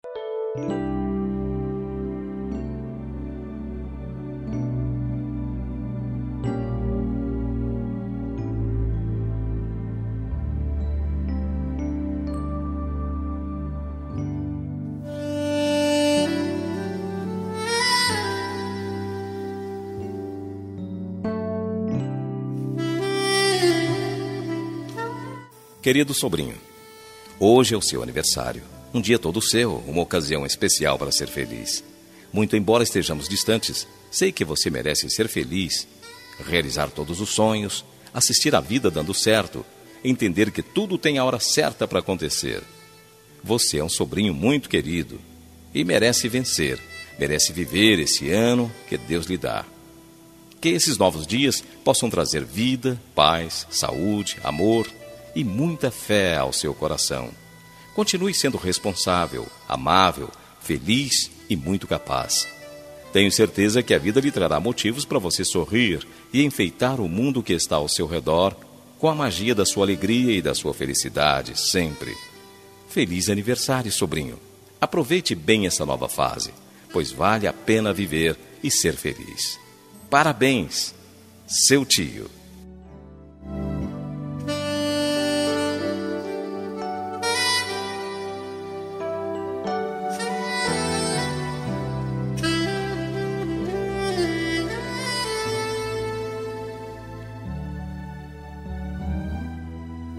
Aniversário de Sobrinho – Voz Masculina – Cód: 4273 – Distante
2679-sobrinho-distante-masc.m4a